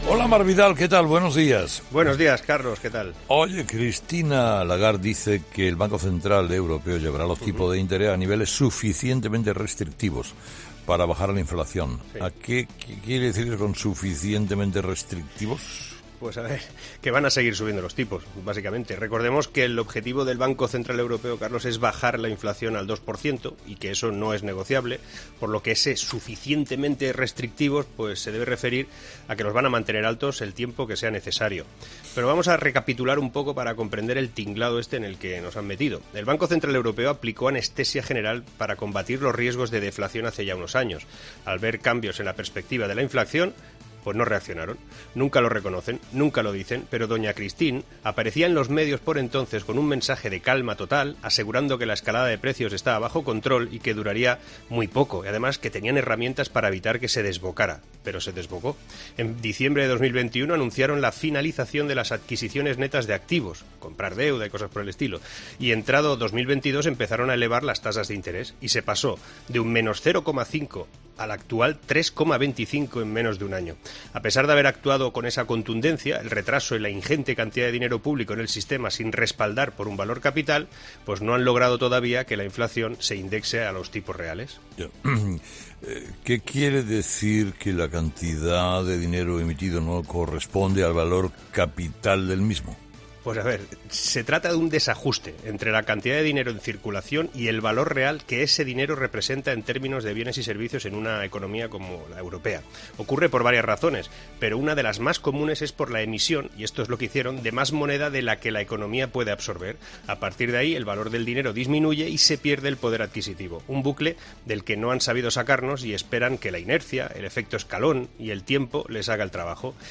El director y presentador de 'Herrera en COPE', Carlos Herrera, le ha preguntado al analista económico por el significado del anuncio hecho por la presidenta del Banco Central Europeo (BCE), Christine Lagarde, que "llevará los tipos de interés a niveles suficientemente restrictivos para bajar la inflación".